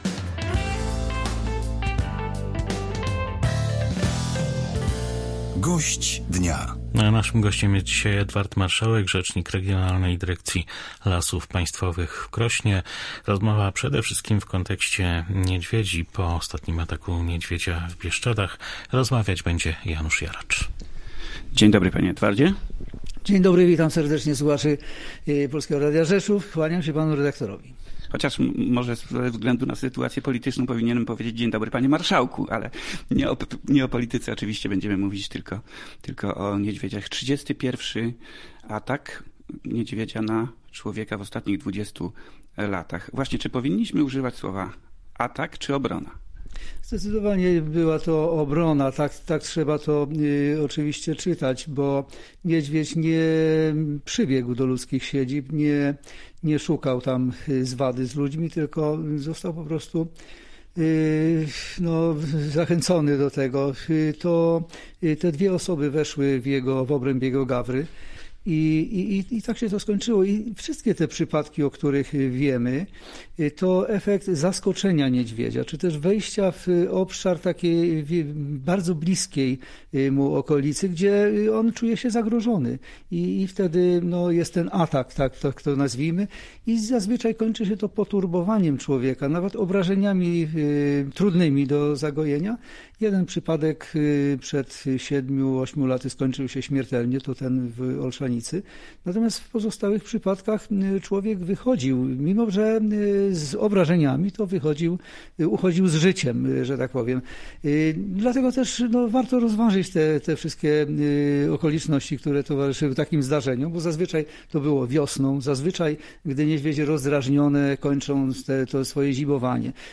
– wyjaśnia gość Polskiego Radia Rzeszów.